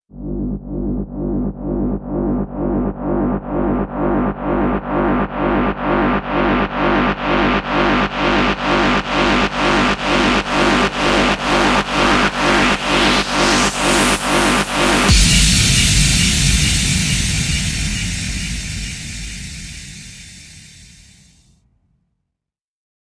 Звуки ожидания
Звук тревожного ожидания